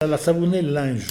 Elle provient de Petosse.
Catégorie Locution ( parler, expression, langue,... )